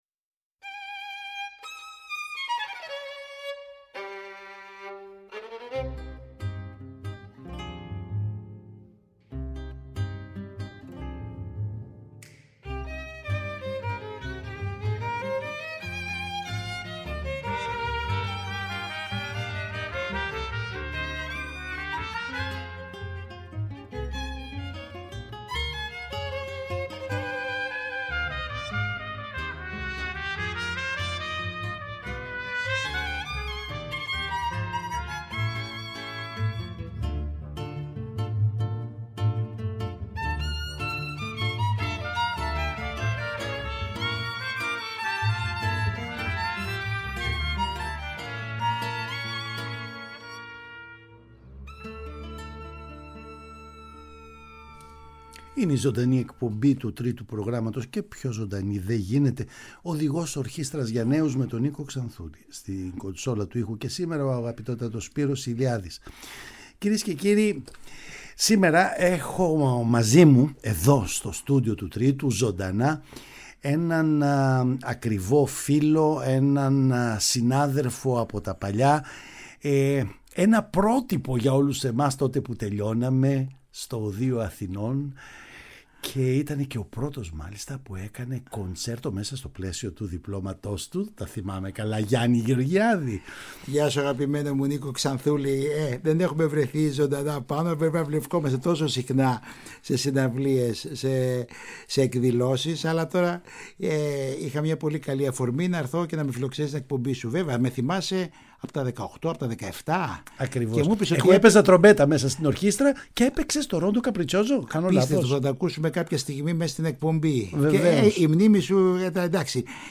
Εργα για Βιολι